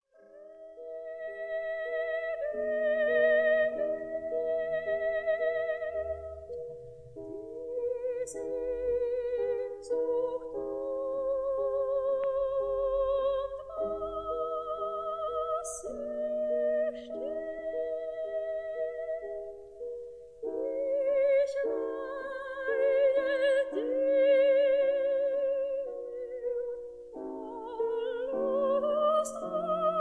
(soprano)
(piano)
Sofiensaal, Vienna